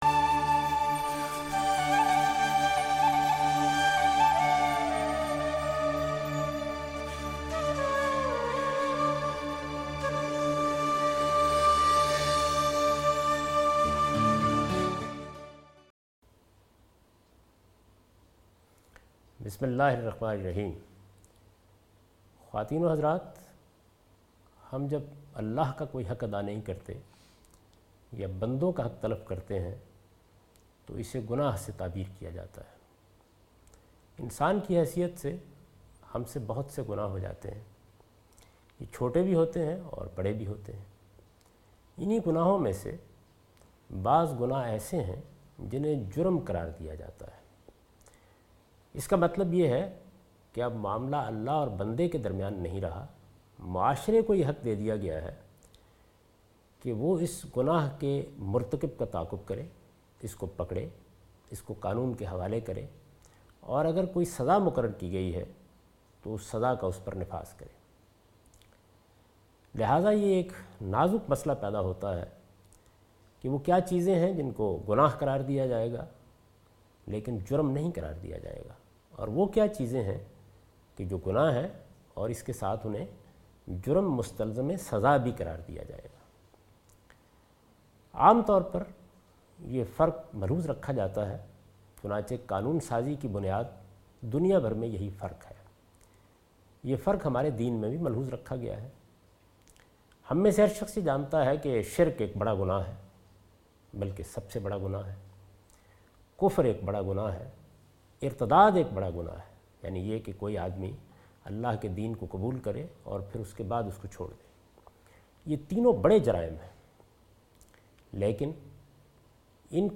This series contains the lecture of Javed Ahmed Ghamidi delivered in Ramzan.